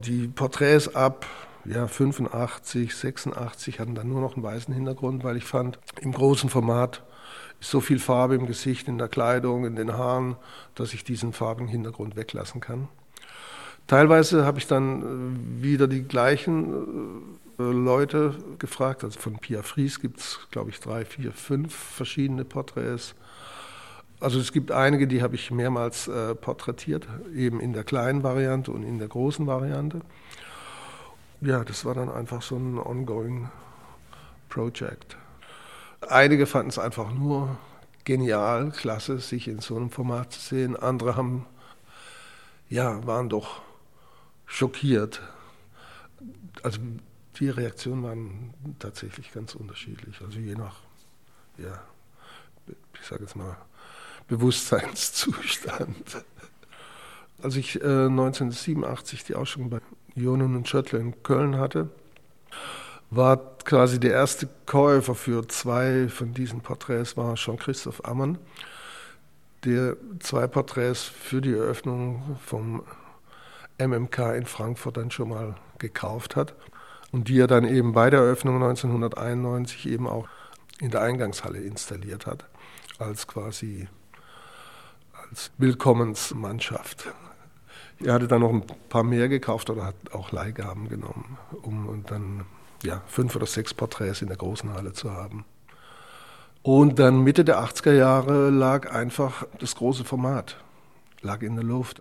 Interview Audioarchiv Kunst: Thomas Ruff über Bernd Becher als Lehrer